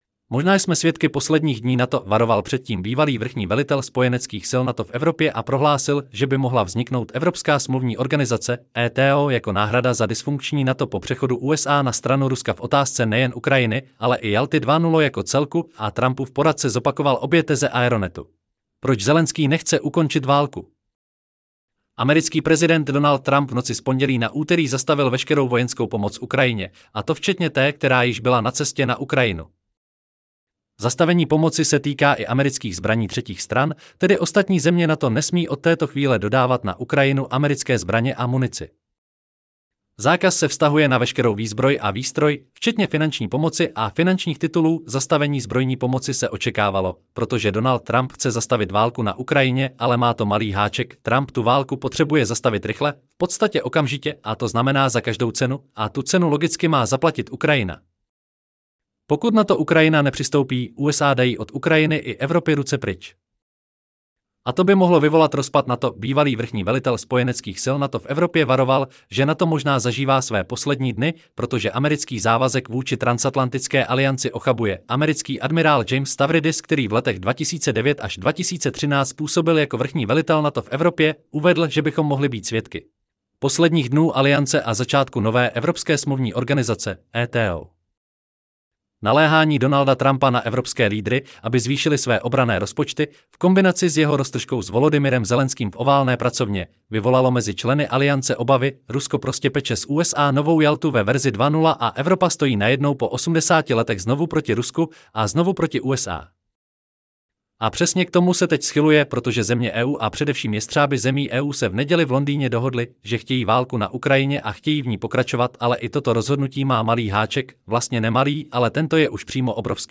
Celý článek si můžete poslechnout v audioverzi zde: Mozna-jsme-svedky-poslednich-dni-NATOVaroval-pred-tim-byvaly-vrchni-velitel-spojeneckych-sil-NATO 5.3.2025 Možná jsme svědky posledních dní NATO!